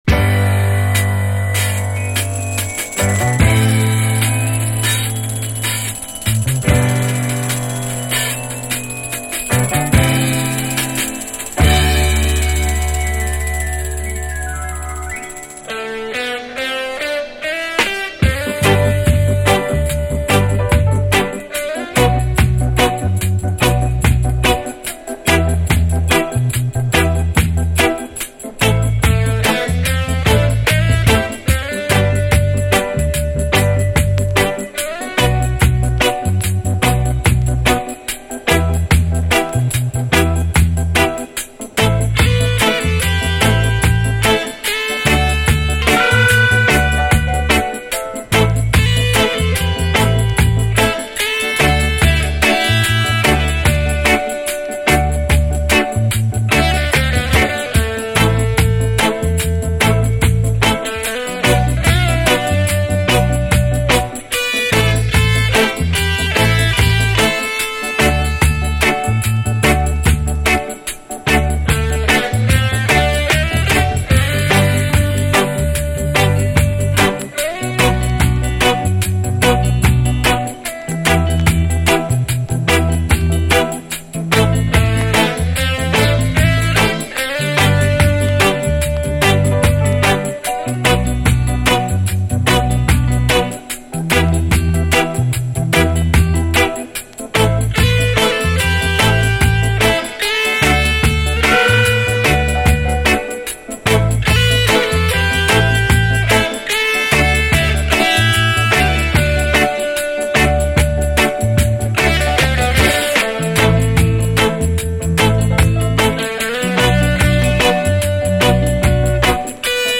REGGAE
A1にチリプチノイズ、B1中盤に周回プチノイズ有。試聴ファイルはこの盤からの録音です